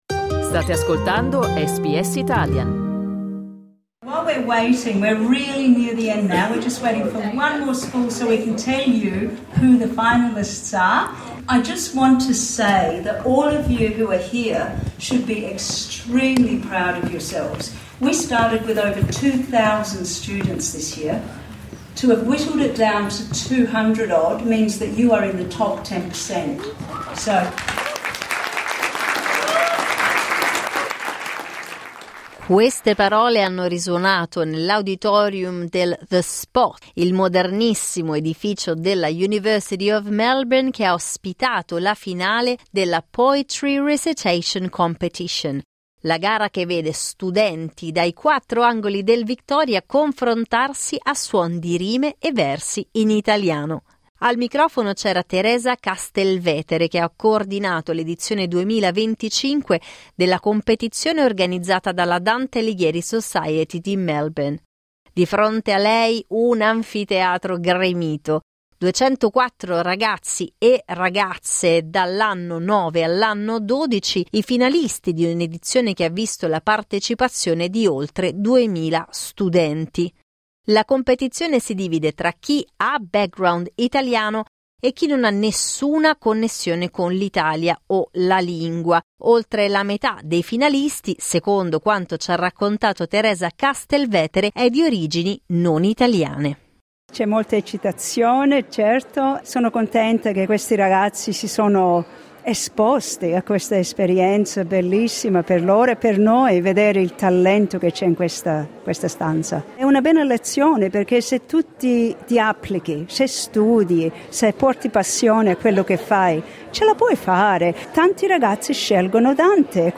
Clicca sul tasto "play" in alto per ascoltare le voci degli studenti, delle insegnanti e degli organizzatori della competizione La competizione si divide tra chi ha background italiano e chi non ha nessuna connessione con l'Italia o l'italiano.